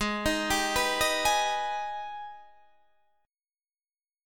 G#mM7 Chord